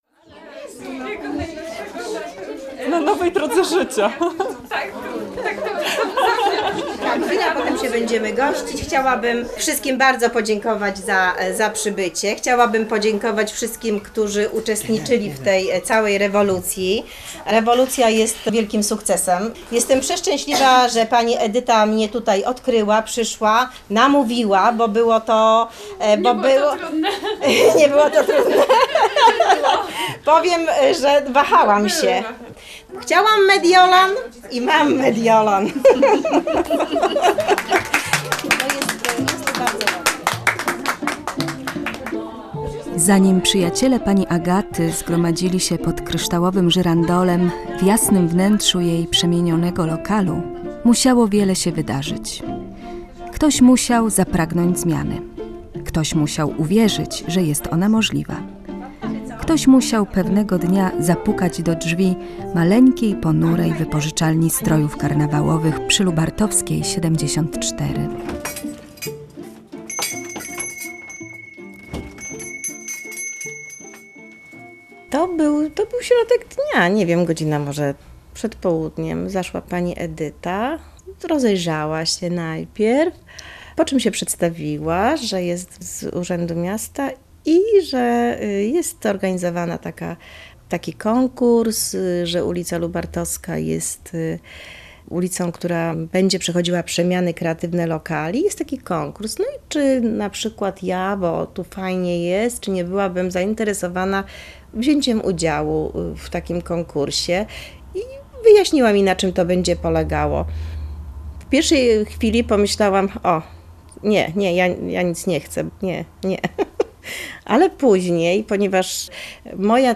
Od początku roku, pod koniec każdego miesiąca zapraszamy Państwa na dźwiękowy spacer do wybranej dzielnicy Lublina.